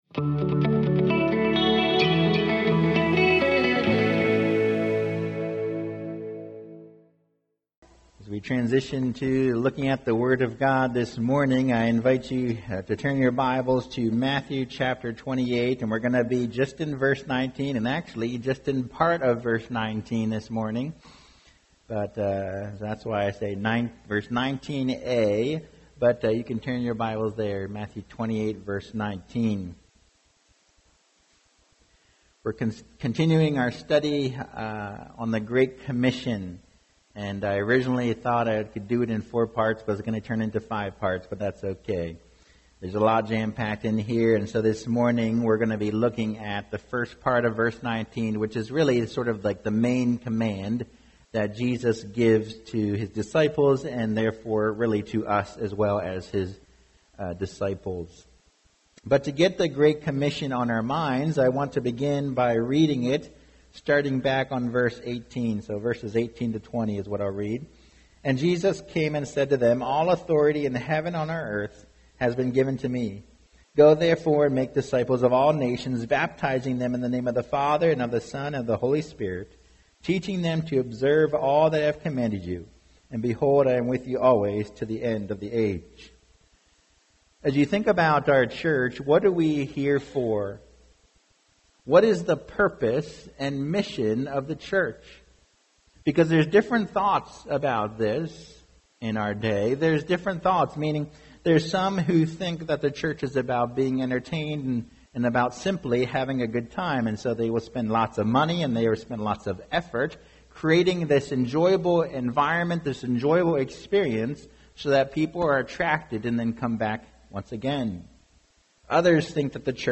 2024 Disciples Make Disciples Preacher